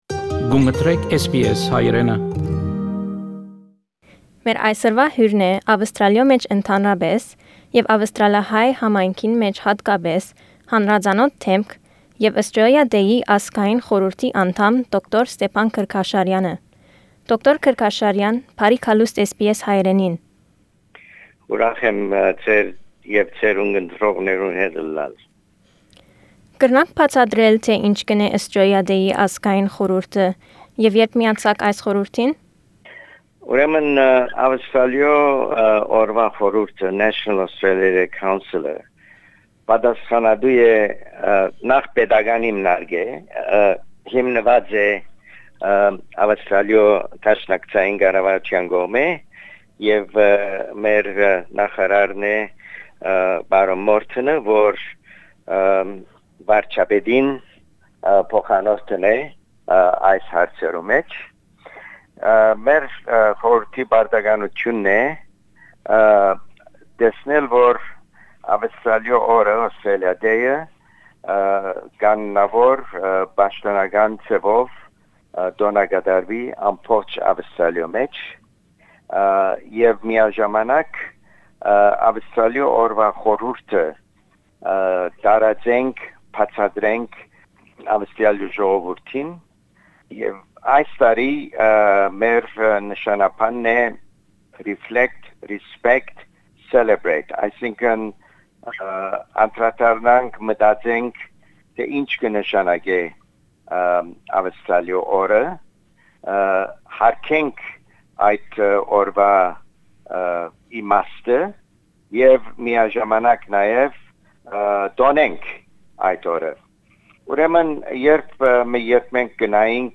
Interview with Dr. Stepan Kerkyasharian AO, board member of the National Australia Day Council about Australia Day.